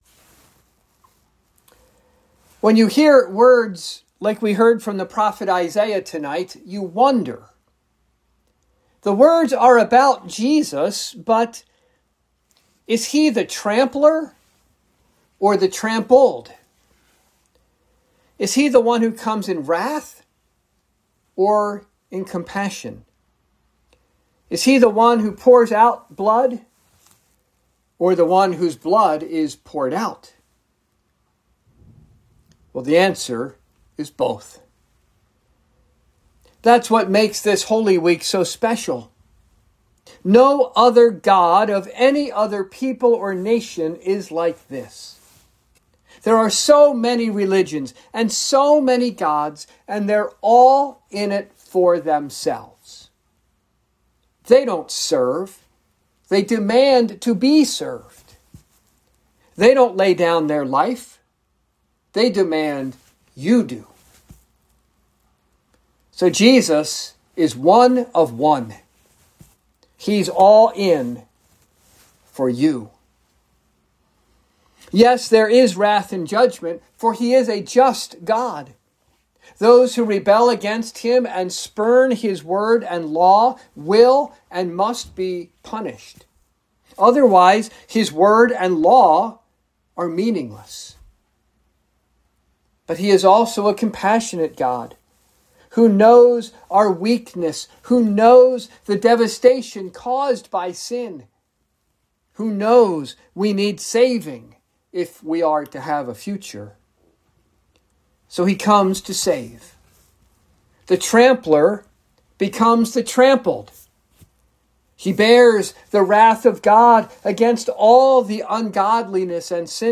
Holy Wednesday Meditation